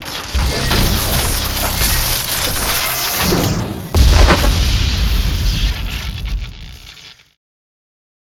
energyball.wav